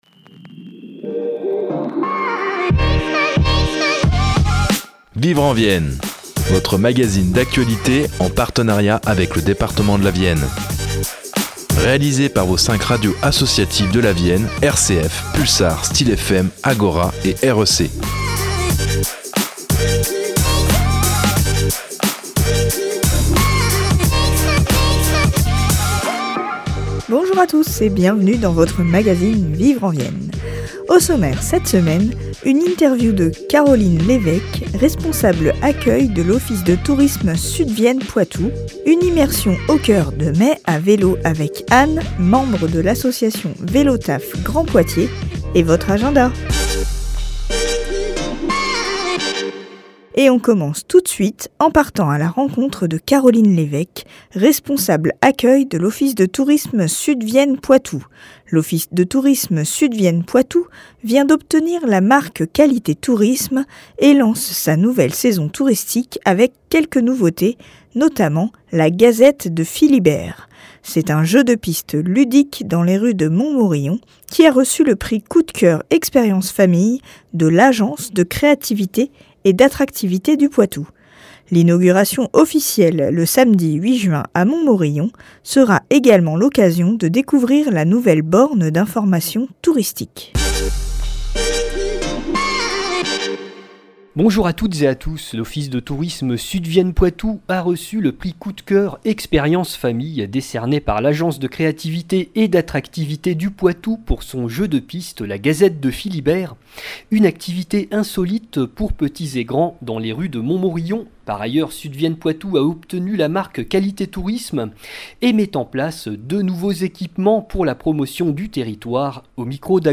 Vivre en Vienne est un magazine d’actualité réalisé par les radios associatives de la Vienne : Radio Agora, Radio Pulsar, RCF Poitou, Styl’FM et Radio•REC, soutenues par le Département de la Vienne.